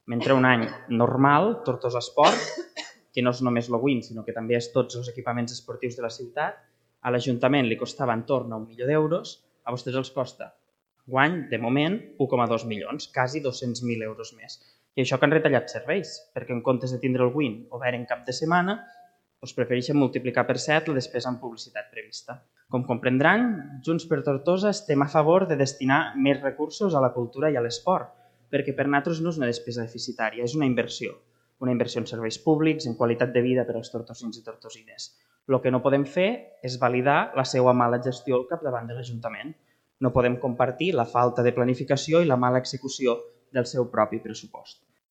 D’altra banda, el ple extraordinari ha aprovat dues modificacions pressupostàries, una de poc més de 229.000 euros per transferir aportacions a Tortosaesport i diferents entitats, amb el vot favorable de tots els grups i l’abstenció de Junts. El regidor de Junts per Tortosa, Òscar Ologaray, ha criticat la gestió del govern municipal mentre la regidora d’hisenda, Maria Jesús Viña, ha defensat l’aportació extraordinària i ha recordat el mal estat de les instal.lacions esportives amb què s’ha trobat l’executiu…
Ologaray-ple_modifpressup_culturaiespots.mp3